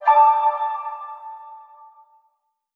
achievment_01.wav